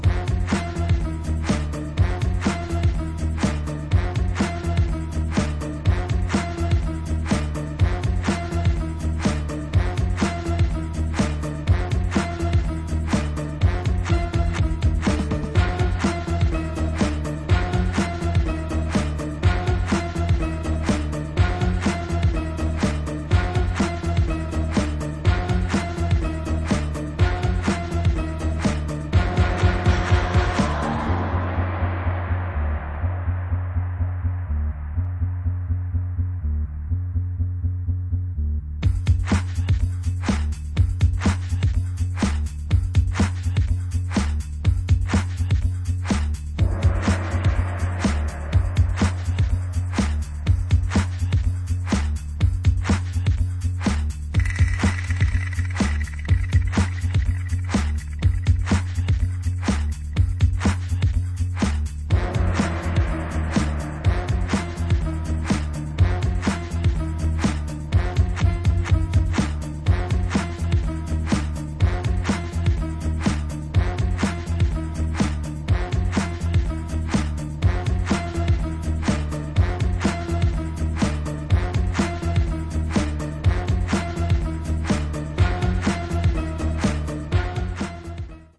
ディスコ・ダブ